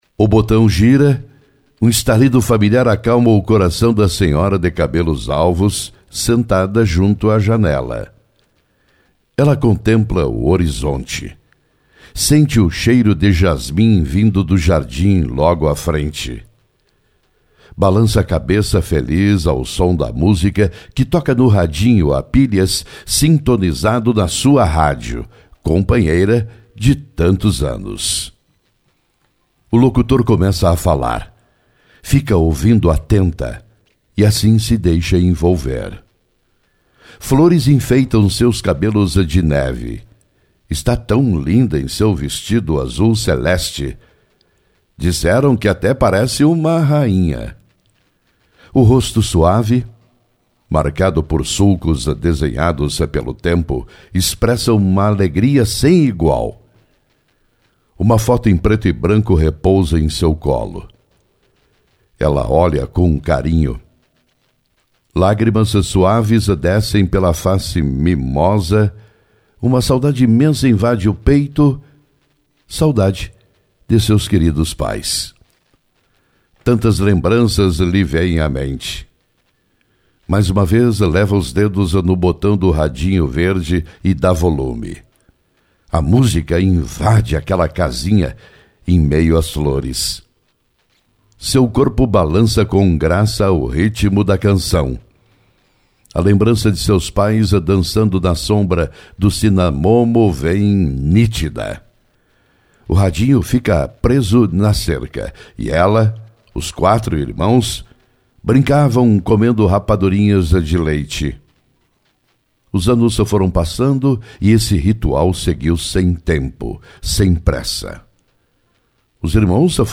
Editorial de 74 anos da Rádio Santiago!